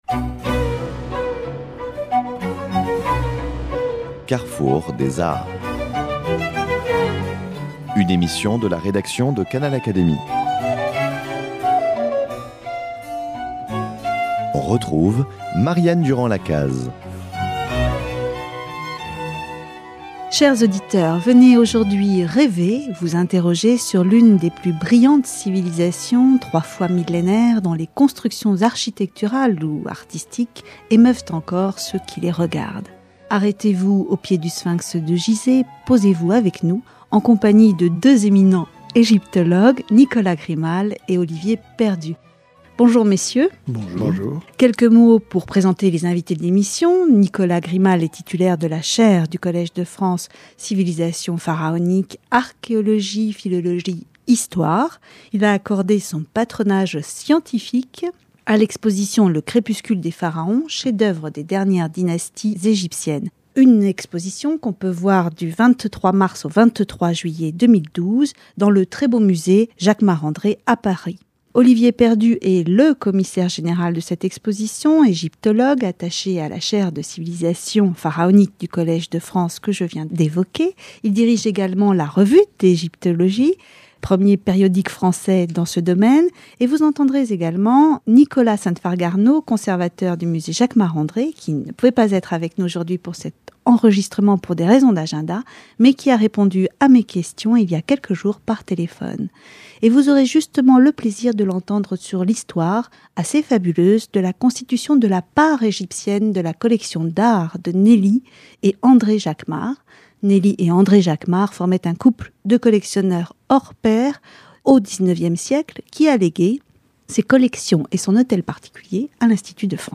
interviewé séparément
par téléphone